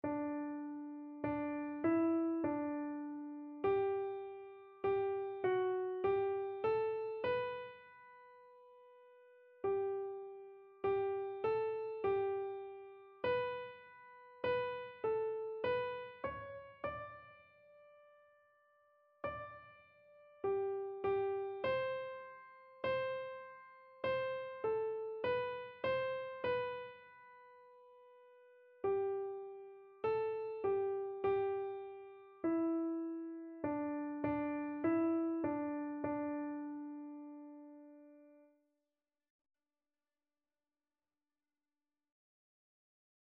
Christian
Free Sheet music for Keyboard (Melody and Chords)
2/2 (View more 2/2 Music)
Keyboard  (View more Intermediate Keyboard Music)
Classical (View more Classical Keyboard Music)